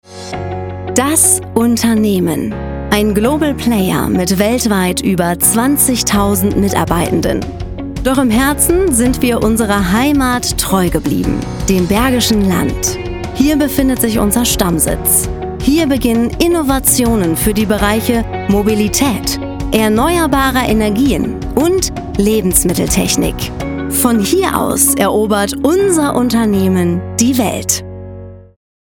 markant, dunkel, sonor, souverän
Mittel minus (25-45)
Off, Presentation